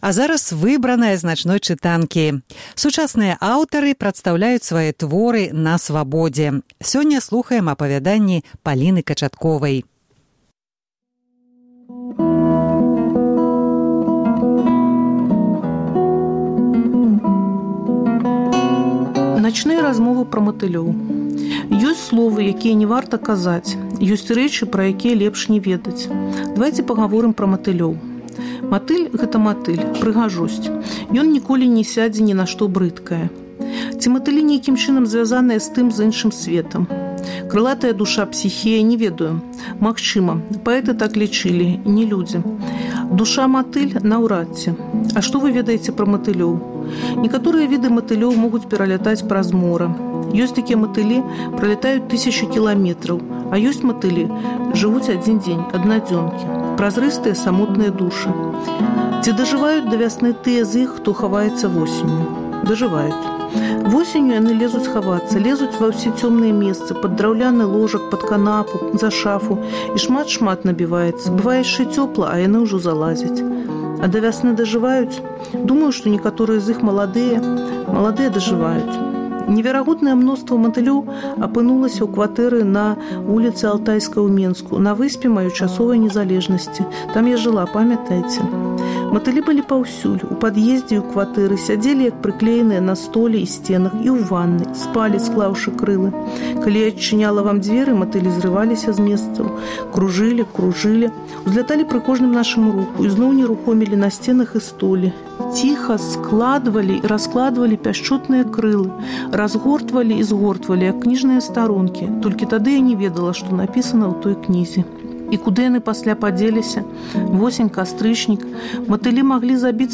Увосень мы паўтараем перадачы з архіву Свабоды. У «Начной чытанцы» — 100 сучасных аўтараў чыталі свае творы на Свабодзе.